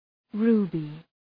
{‘ru:bı}